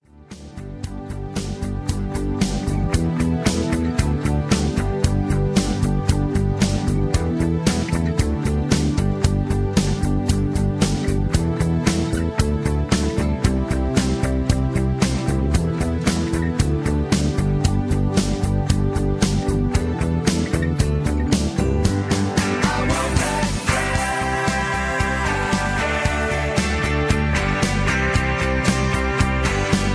Key-G) Karaoke MP3 Backing Tracks
Just Plain & Simply "GREAT MUSIC" (No Lyrics).